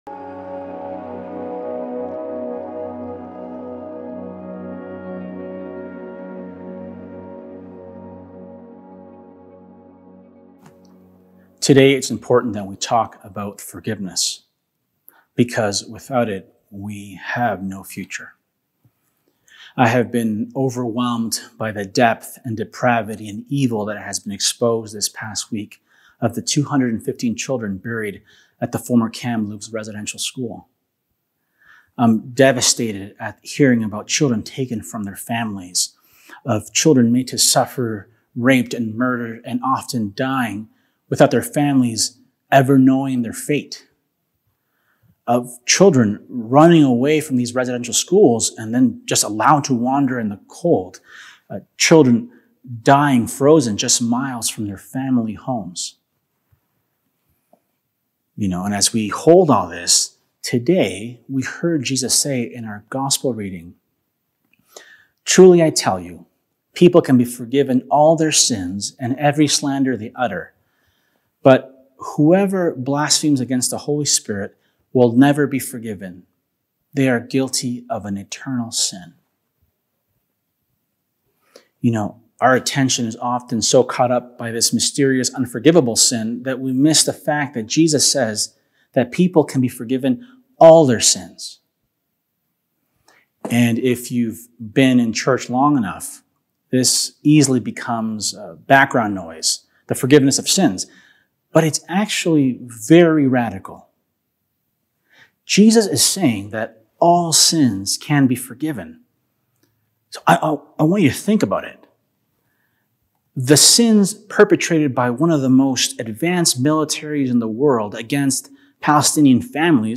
Sermons | St. Michael Anglican Church